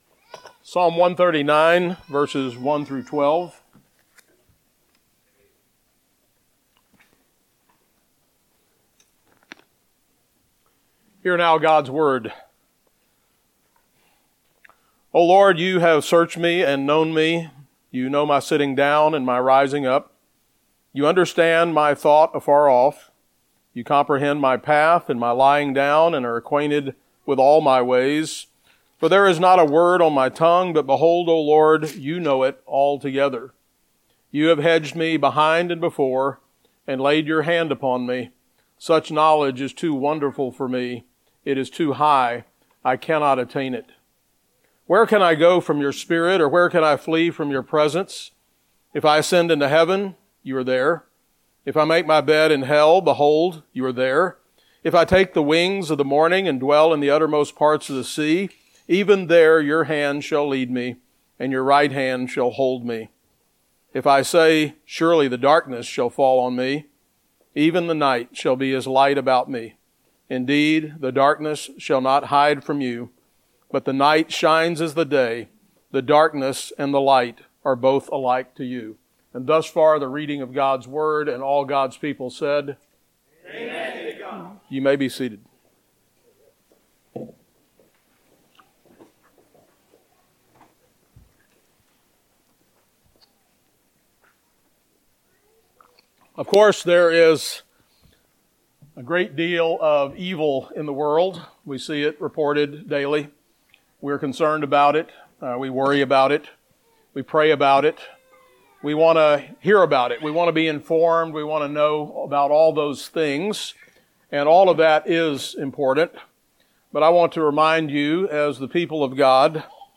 Psalm 139:1-12 Service Type: Sunday Sermon Download Files Bulletin « Living in the Covenant What is in a Name?